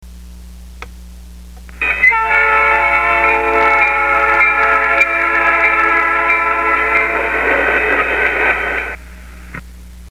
To my ear they are all very similar and of good quality.
Playback through a larger, higher quality speaker
train_crossing_stock_bone_mic_goodspeaker.mp3